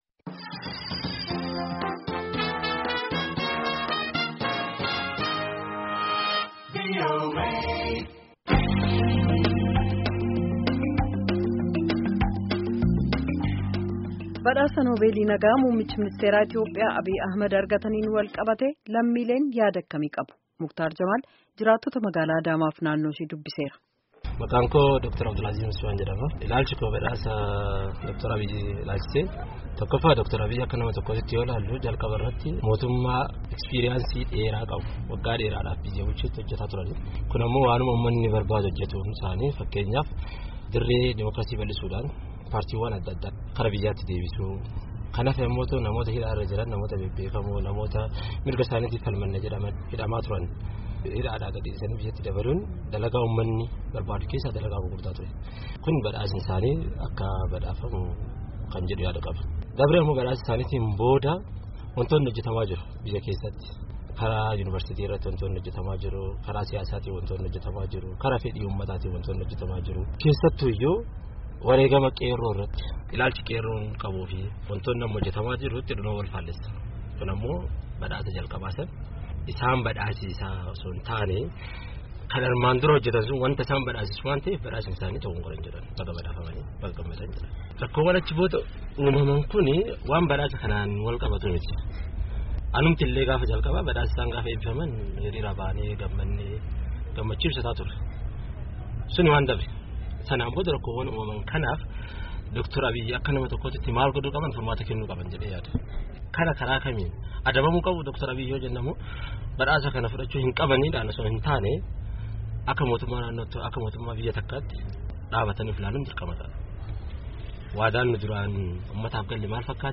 jiraattota Magaala Adaamaaf naanno isii dubbisee jira.